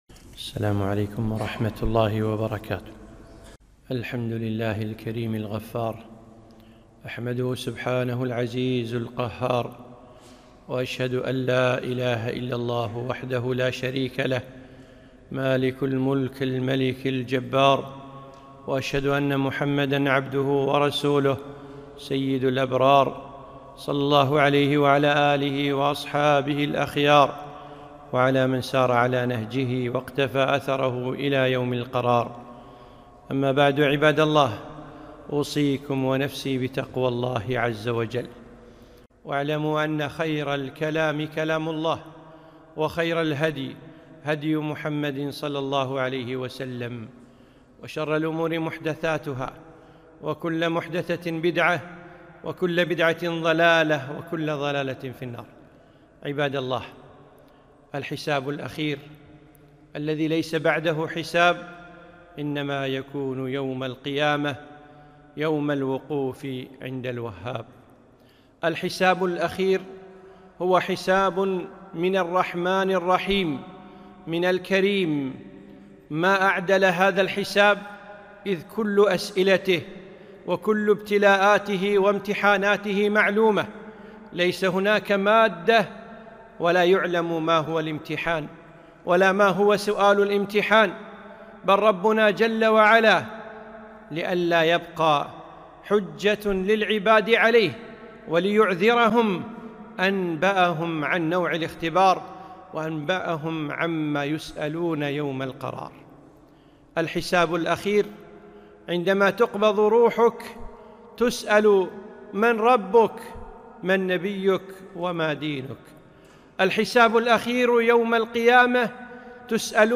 خطبة - الحساب الأخير